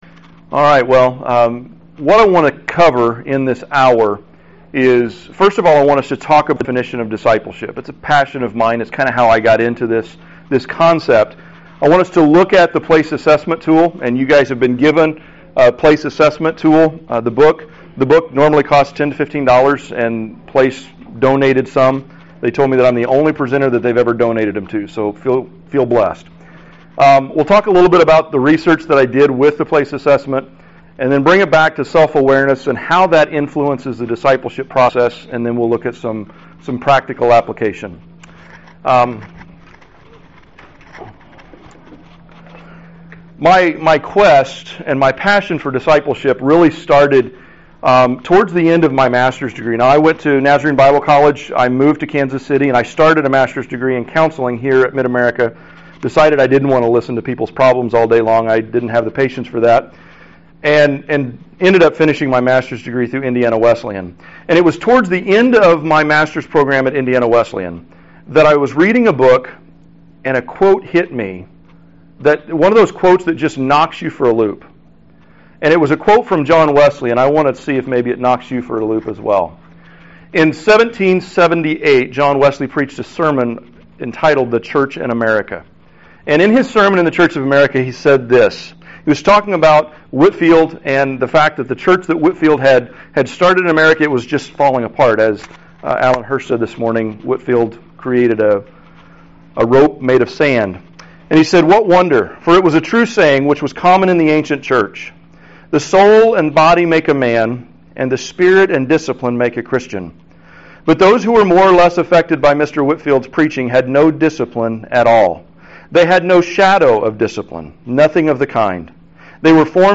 Learn how to empower your people to better serve and live in community through the discovery and development of their strengths and personality. The presenter will relate recent research as well as practical experience to the development of disciples through self-awareness.